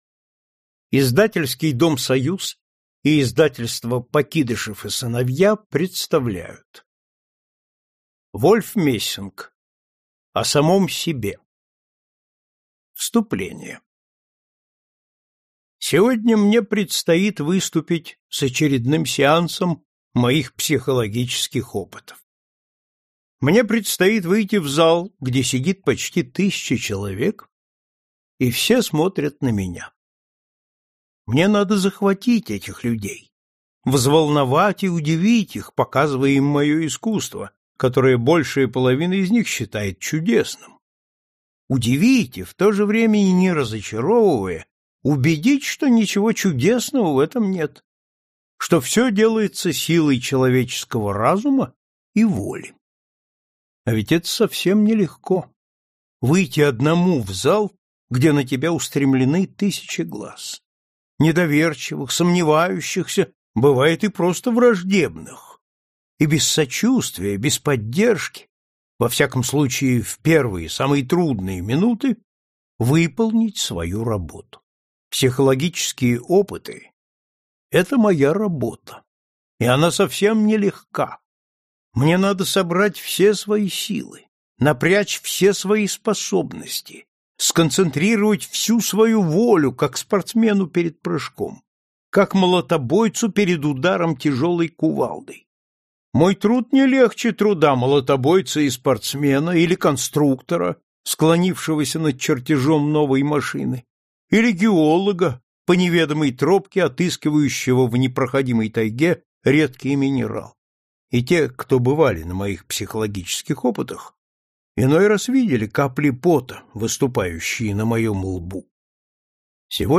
Аудиокнига О самом себе | Библиотека аудиокниг